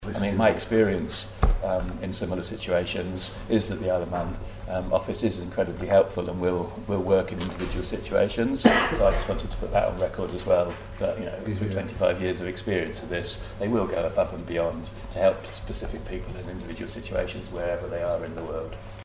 Chris Thomas was answering a question on the wet-ink stamp in the House of Keys
Answering a visa-related question in the House of Keys this week, Chris Thomas said all three Crown Dependencies use the same immigration documents and are facing the same situation as the Island.